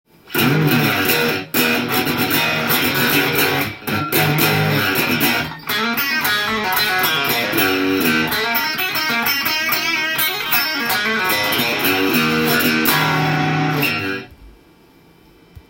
試しに弾かせて頂きました
リアのピックアップで弾いてみました。
凄いジャキジャキ感です。さすがテキサススペシャル。
驚きのサウンドになっています。